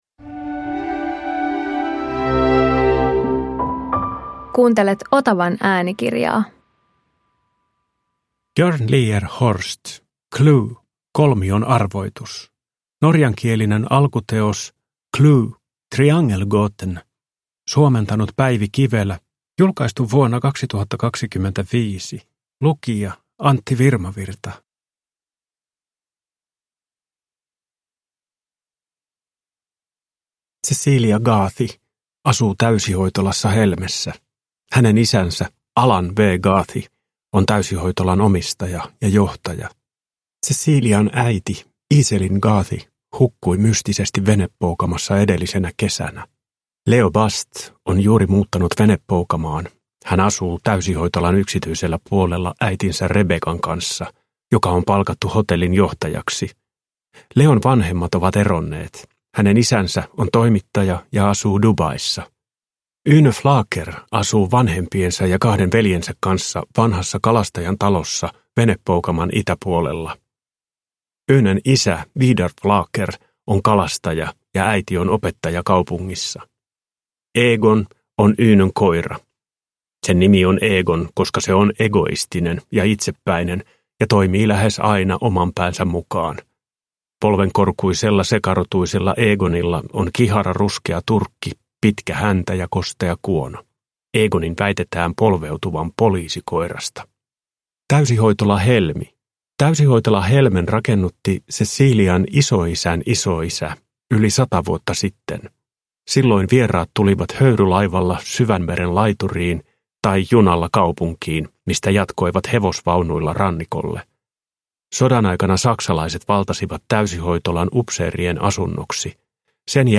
CLUE – Kolmion arvoitus – Ljudbok